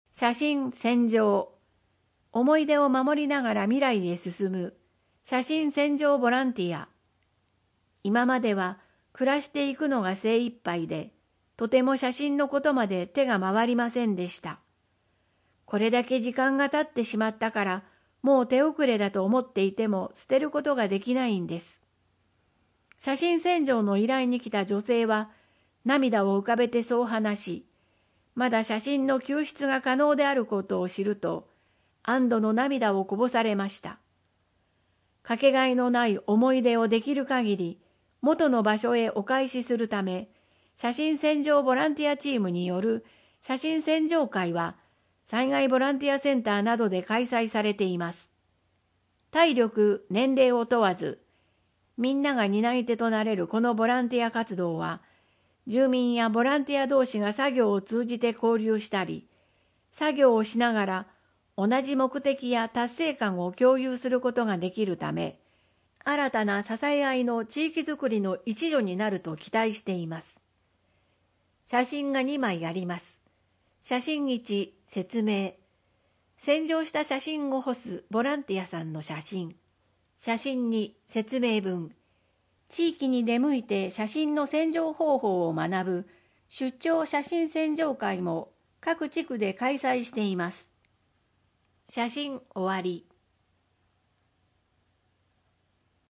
豪雨ニモマケズ（音訳版）